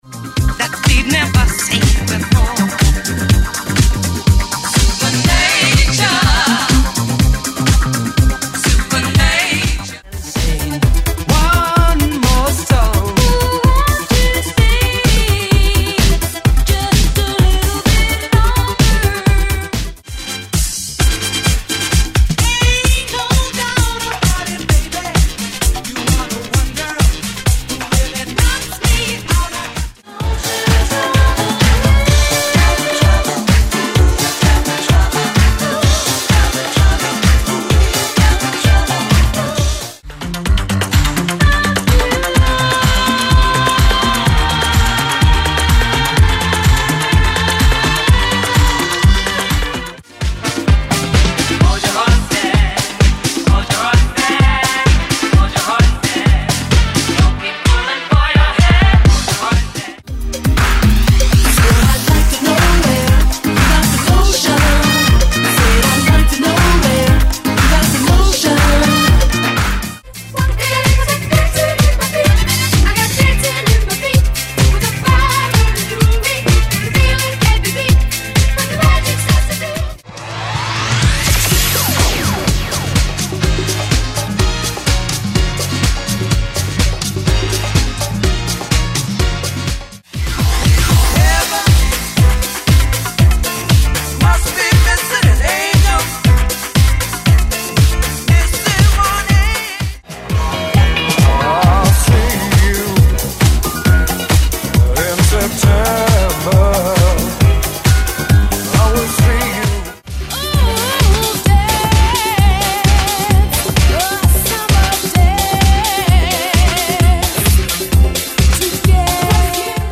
Genre: 80's Version: Clean BPM